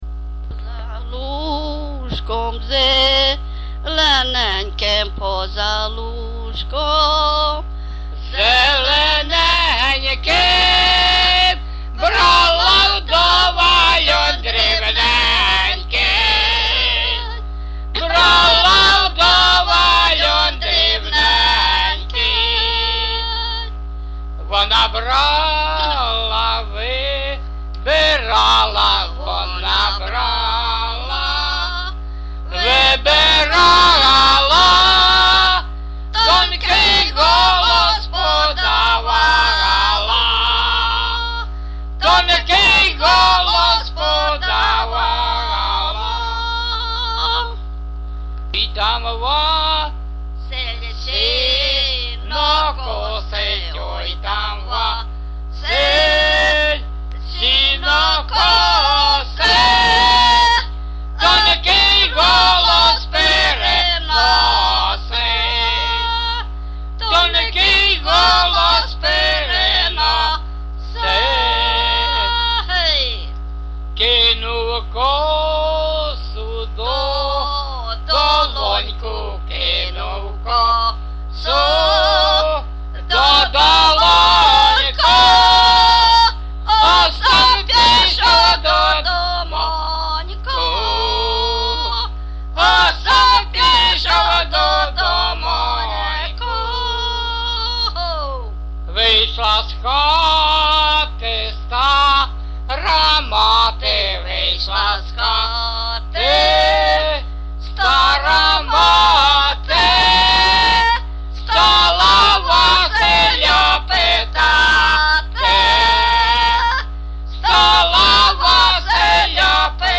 ЖанрПісні з особистого та родинного життя
Місце записус. Нижні Рівні, Чутівський район, Полтавська обл., Україна, Слобожанщина